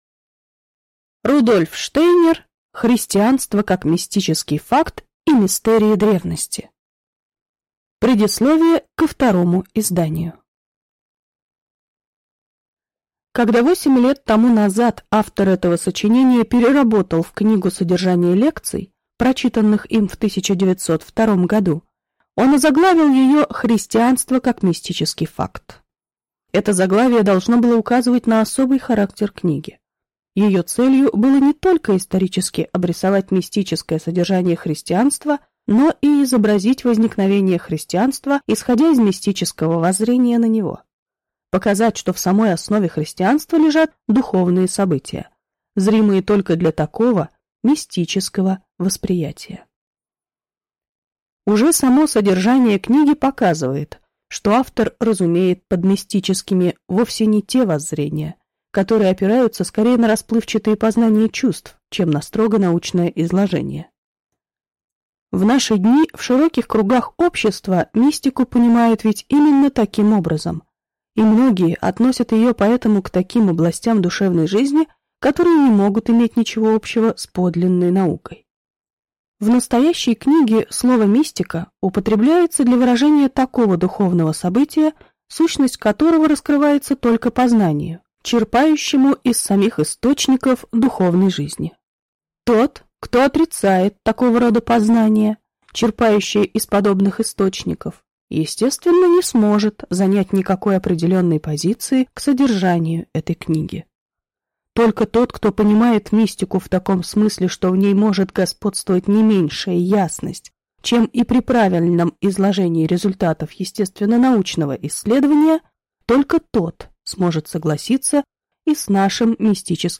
Аудиокнига Христианство как мистический факт и мистерии древности | Библиотека аудиокниг